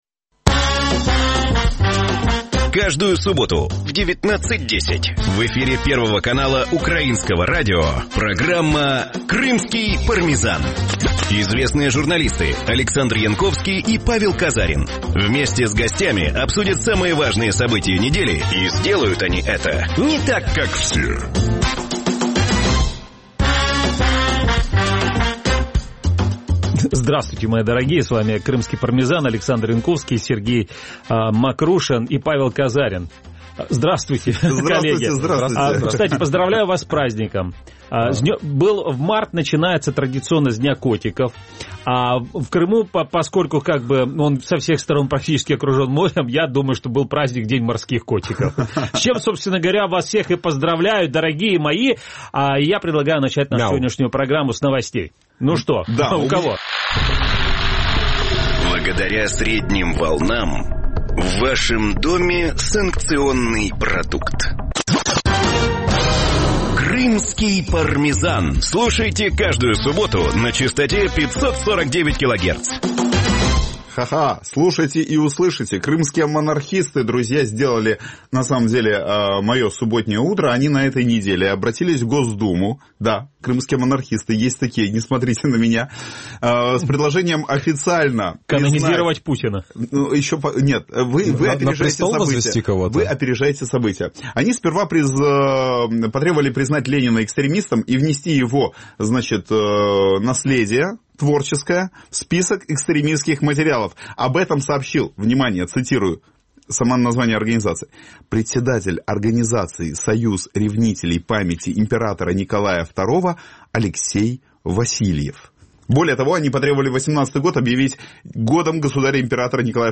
Программа звучит в эфире Радио Крым.Реалии. Время эфира: 18:10 – 18:40 (19:10 – 19:40 в Крыму).